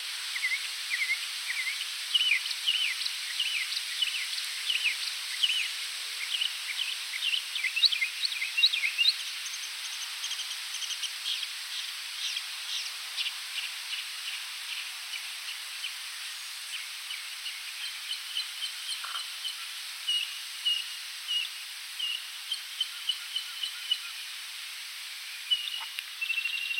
鸟类鸣叫叽叽喳喳
描述：鸟类鸣叫叽叽喳喳。
标签： 鸣叫 鸟叫声 啾啾 叽叽喳喳 鸟类
声道立体声